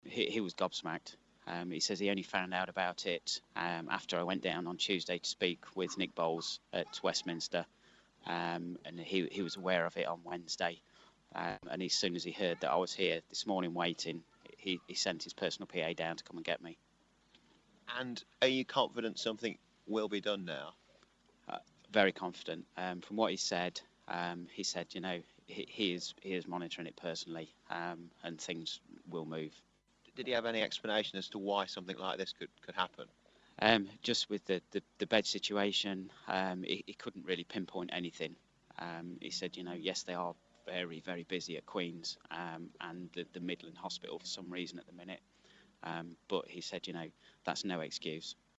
Man describes meeting with Lansley